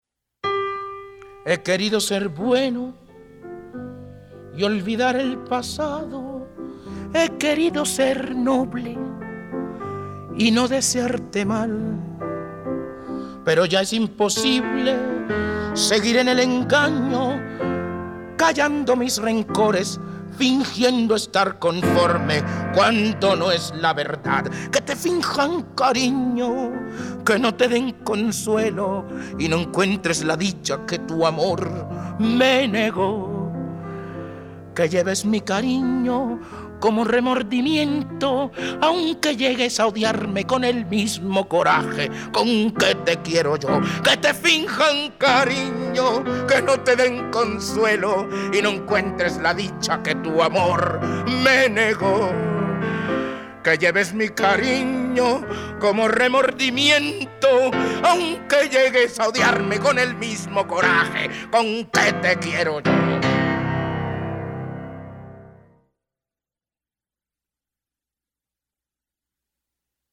Una dramática interpretación